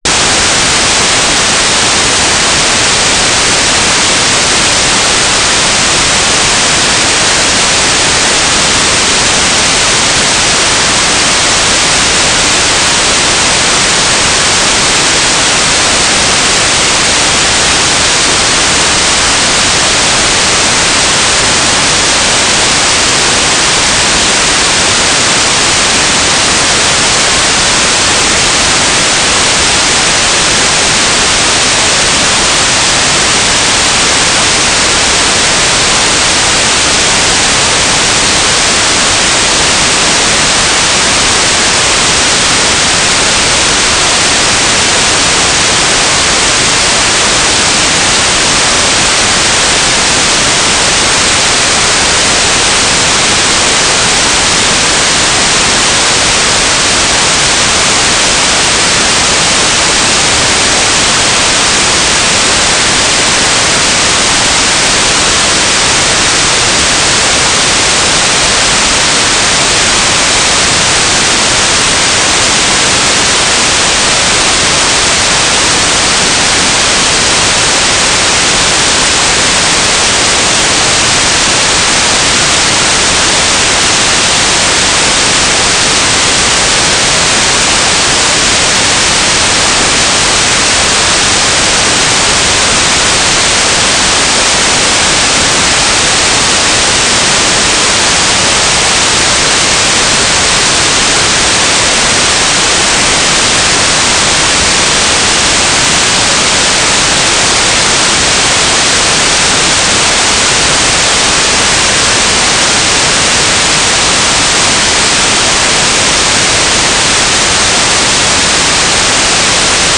"waterfall_status": "without-signal",
"transmitter_description": "9k6 FSK TLM",
"transmitter_mode": "FSK",